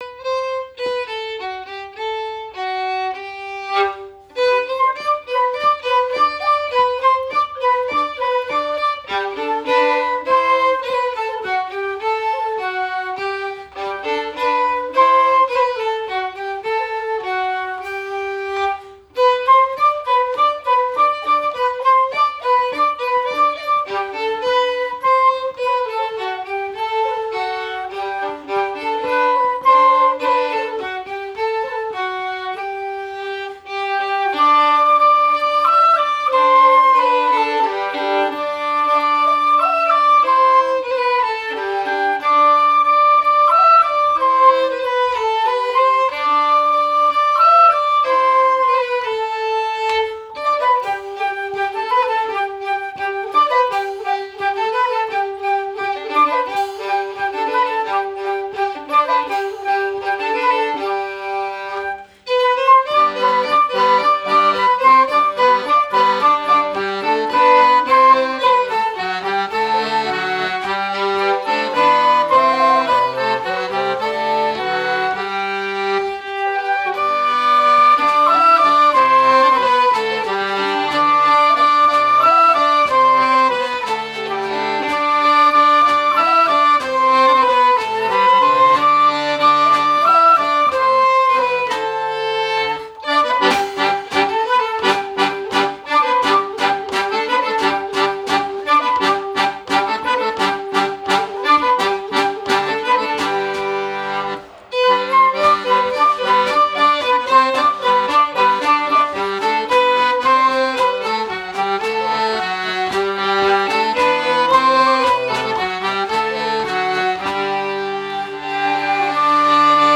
Répétition du 23/06/2012 - Spectacle de Reims - Musique